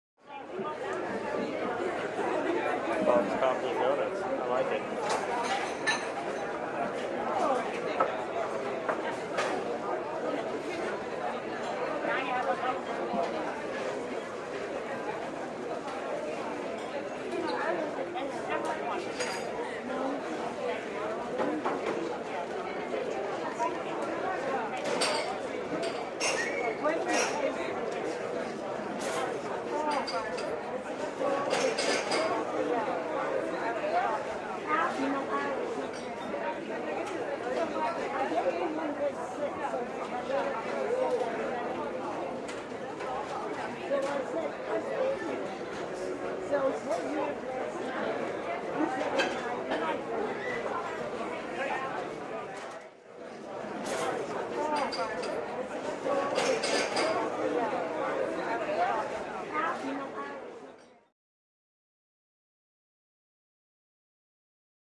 Coffee Shop, Lots Of Movement In Distance, Subdued Voices, Occasional Cup Movement.